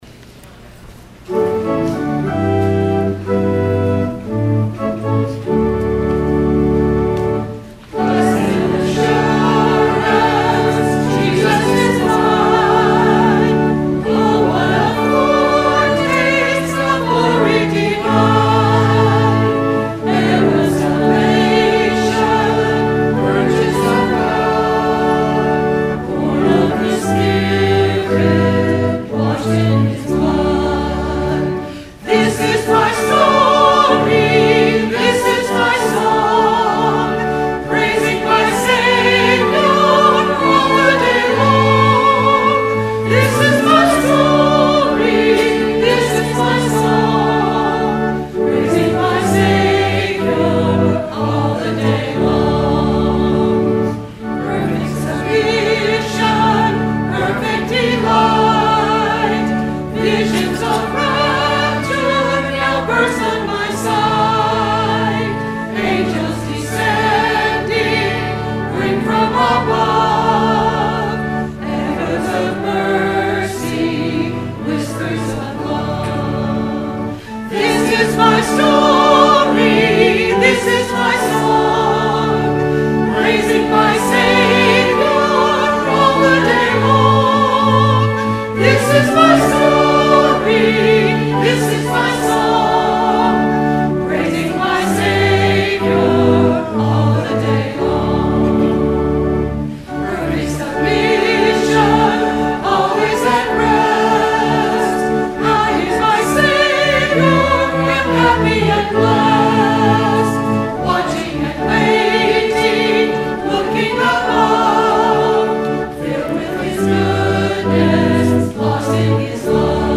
Sung by the Church and Choir.
Hymn